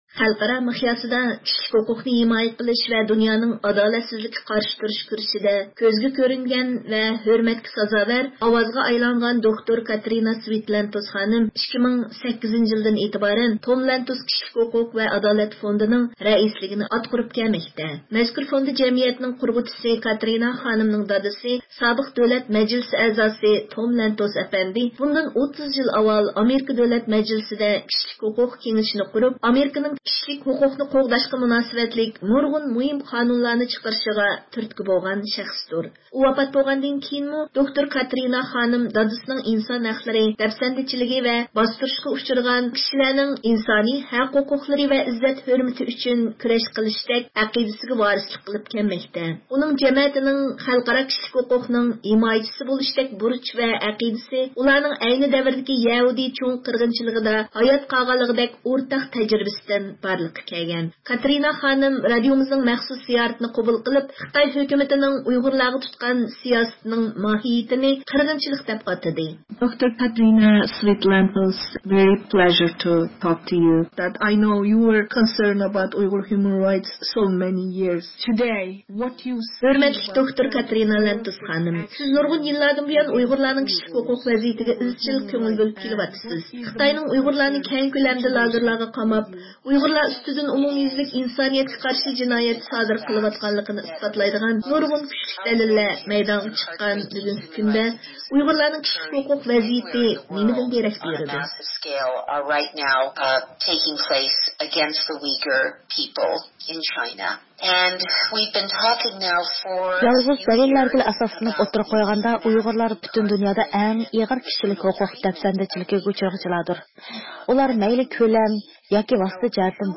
كاترىنا خانىم، رادىيومىزنىڭ مەخسۇس زىيارىتىنى قوبۇل قىلىپ خىتاي ھۆكۈمىتىنىڭ ئۇيغۇرلارغا تۇتقان سىياسىتىنىڭ ماھىيىتىنى «قىرغىنچىلىق» دەپ ئاتىدى.